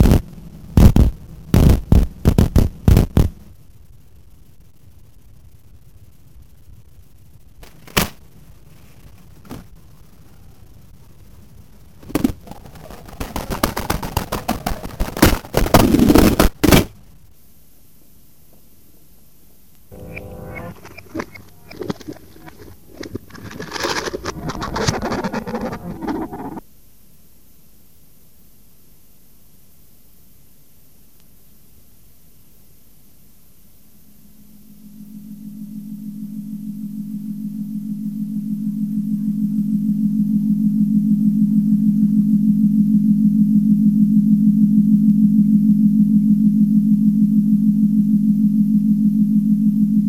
lo-fi noise form